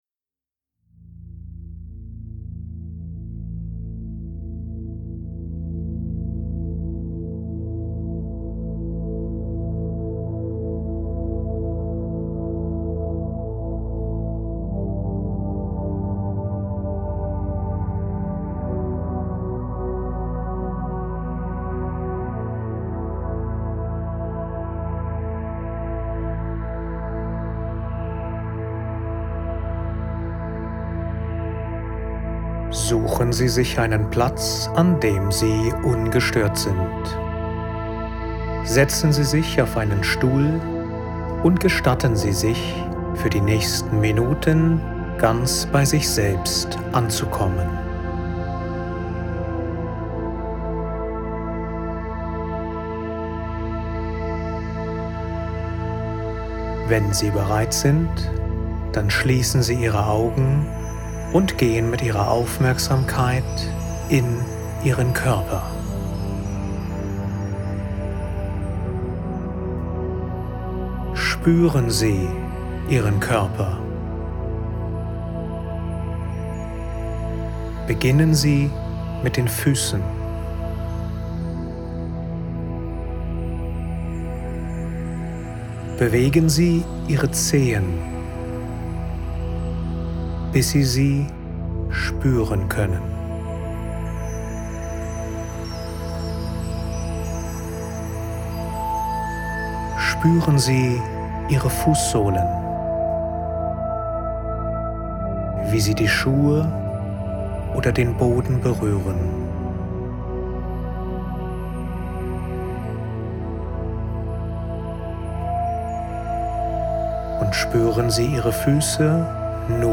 Mehr erfahren Video laden Vimeo immer entsperren Download flowZeit Audio-Programm: Mit dem geführten Audio-Programm flowZeit Der Grundmodus des Leaders trainierst Du Deinen inneren Zustand.